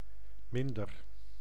Ääntäminen
IPA: [min.dǝɾ]